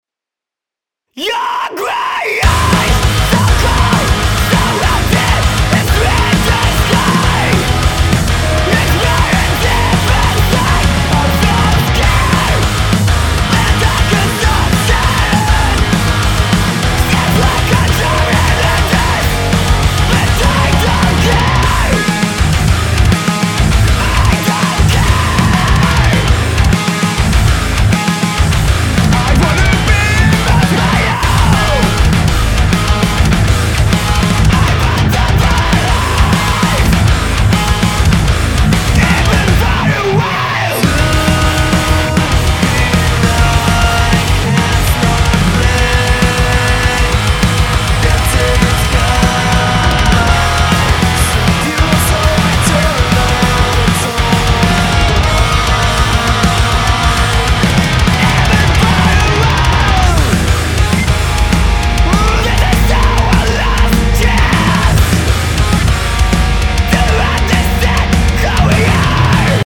Зацените metalcore/post-hardcore